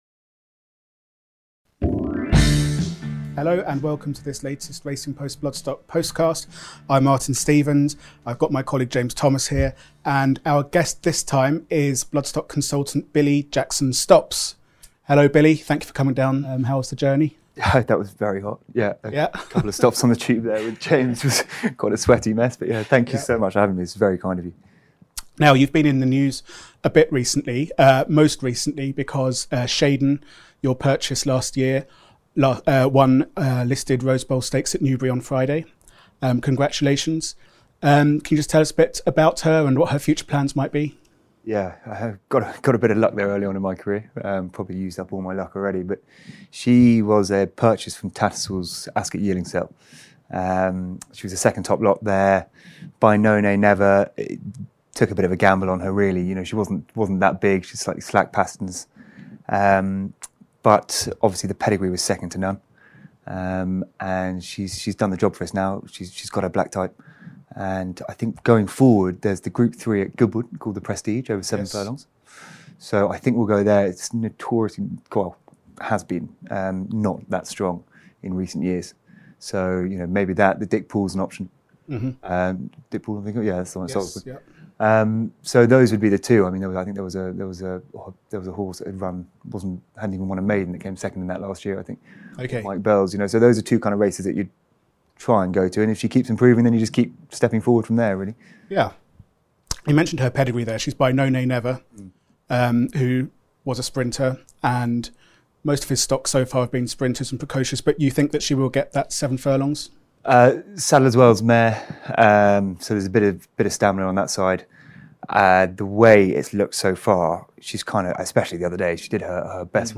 Industry Update